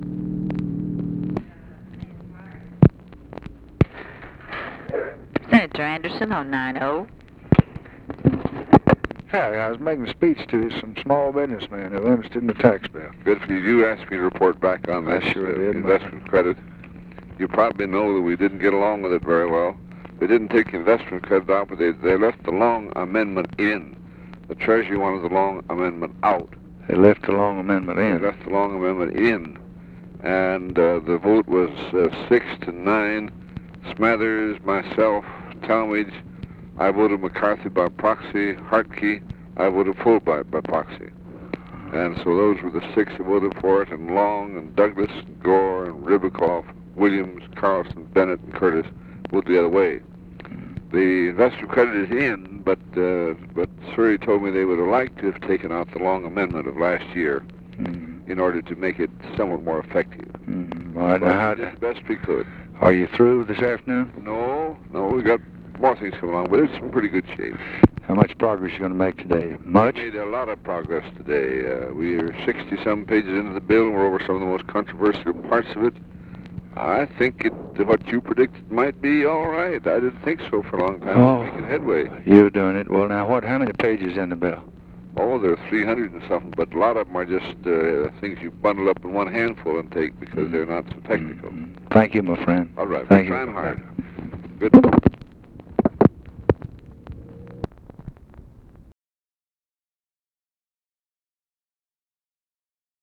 Conversation with CLINTON ANDERSON, January 9, 1964
Secret White House Tapes